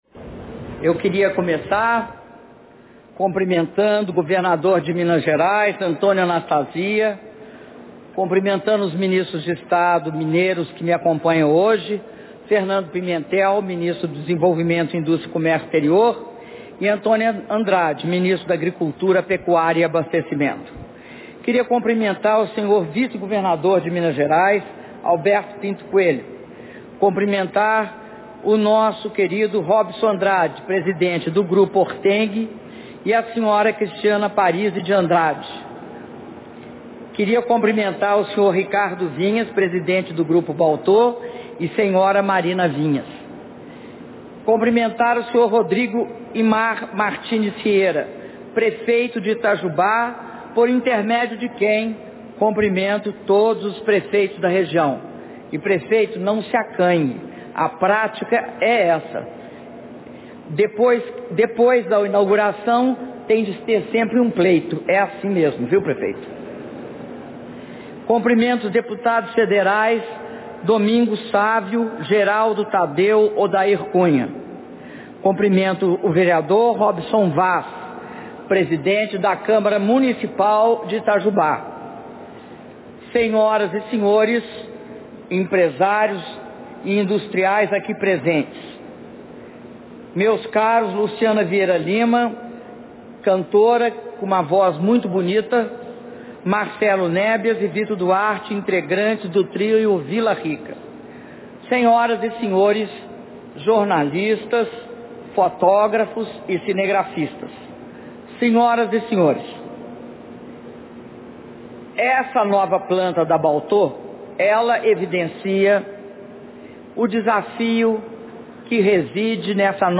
Discurso da Presidenta Dilma Rousseff na cerimônia de inauguração da fábrica de transformadores de corrente e de potencial da Balteau S/A - Itajubá/MG